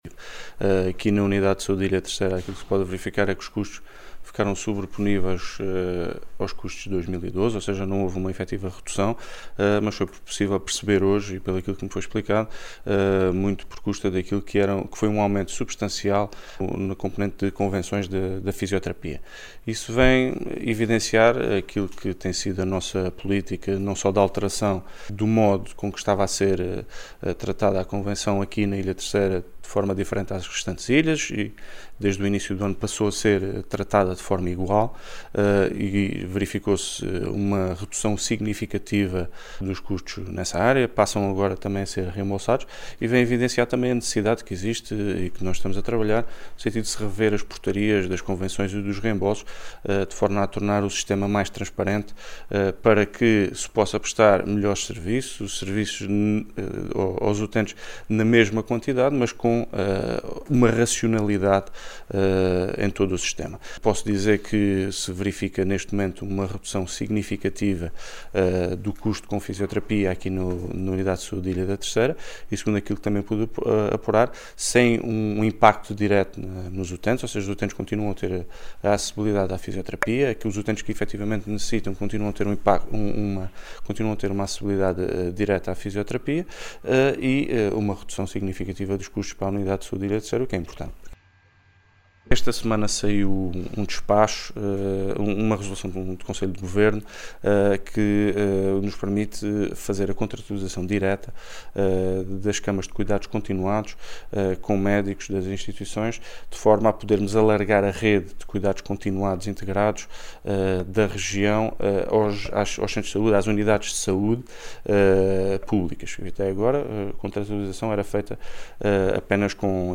Luís Cabral, que falava aos jornalistas à margem de uma reunião com o Conselho de Administração da Unidade de Saúde da Ilha Terceira, frisou que, “com o regime de reembolsos introduzido no início do ano, os custos da Fisioterapia passaram a estar equilibrados com a média das restantes ilhas”, acrescentando que “os utentes continuam a ter a mesma acessibilidade aos tratamentos de Fisioterapia”.